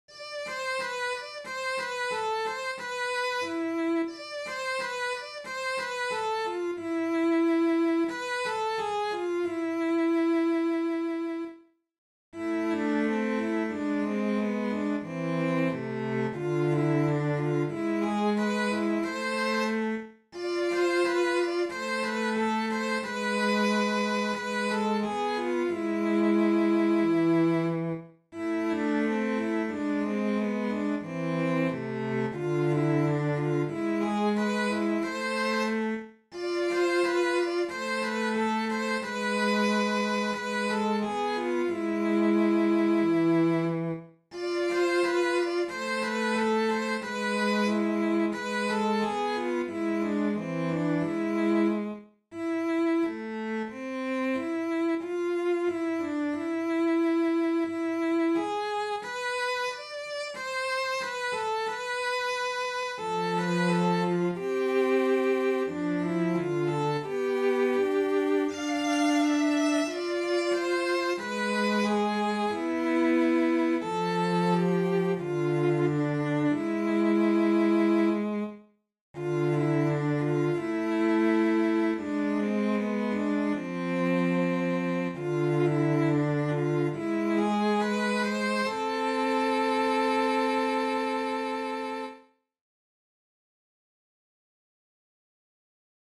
Laine-hiekkaan-hajoaa-sellot.mp3